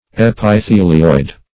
Meaning of epithelioid. epithelioid synonyms, pronunciation, spelling and more from Free Dictionary.
Search Result for " epithelioid" : The Collaborative International Dictionary of English v.0.48: Epithelioid \Ep`i*the"li*oid\, a. [Epithelium + -oid.]